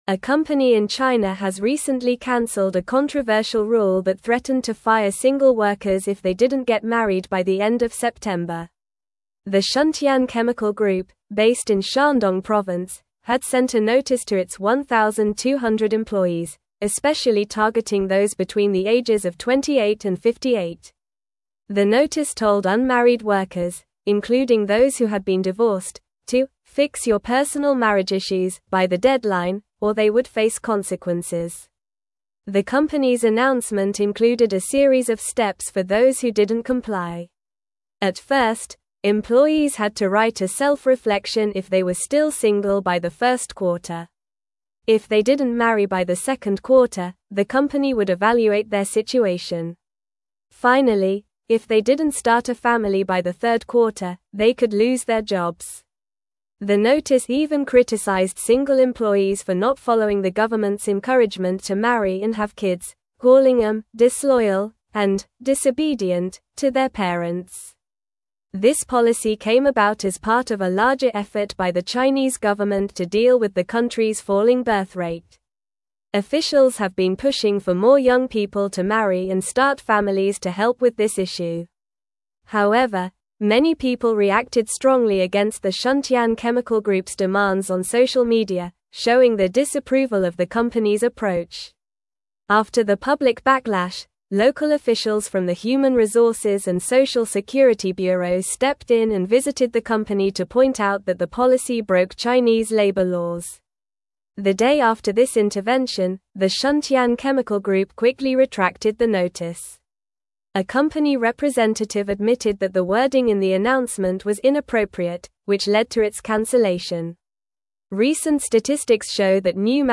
Normal
English-Newsroom-Upper-Intermediate-NORMAL-Reading-Company-Faces-Backlash-Over-Controversial-Marriage-Policy.mp3